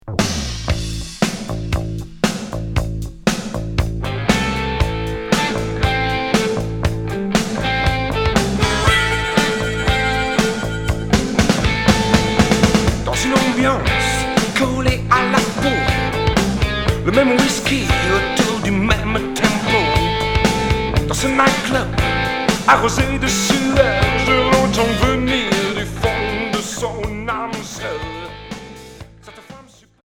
Rock Troisième 45t retour à l'accueil